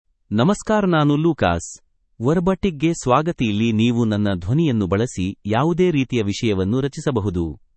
MaleKannada (India)
LucasMale Kannada AI voice
Lucas is a male AI voice for Kannada (India).
Voice sample
Male
Lucas delivers clear pronunciation with authentic India Kannada intonation, making your content sound professionally produced.